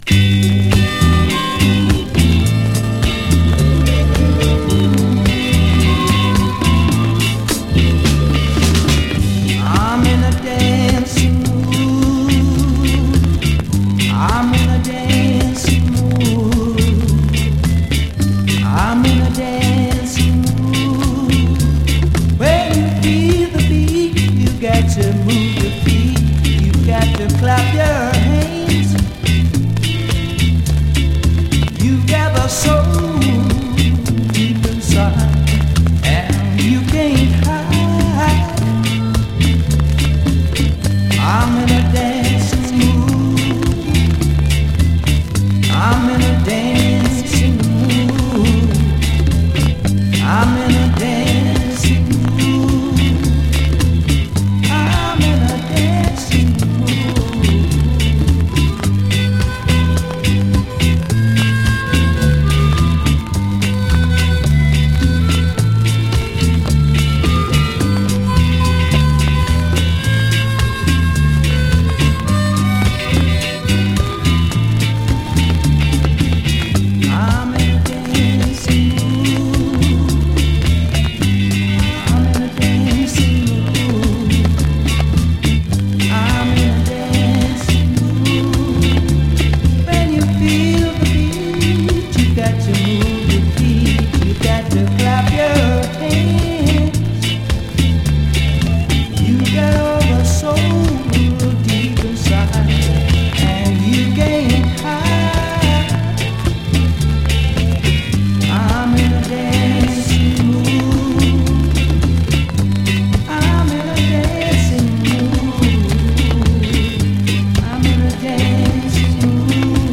has a sweet and lyrical 'tropical' sound
Rock Steady